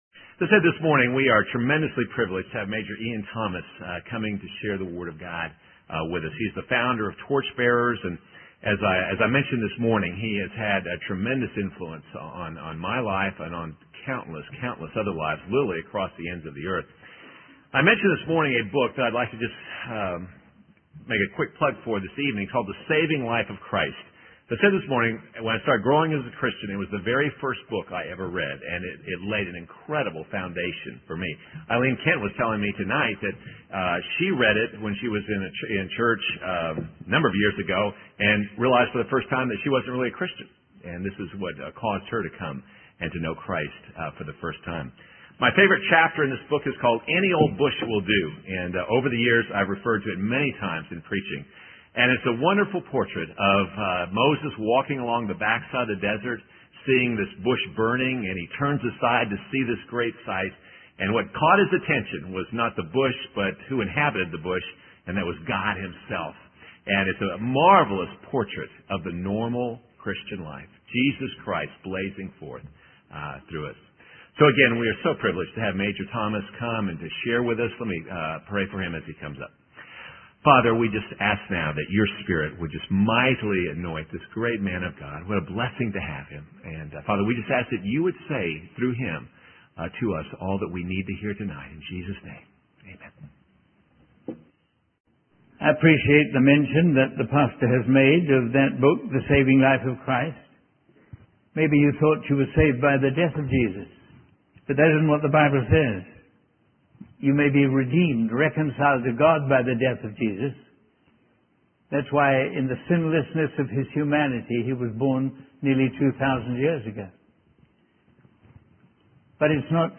In this sermon, the speaker discusses the importance of believing in the resurrection of Jesus.